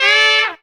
HARM RIFF 3.wav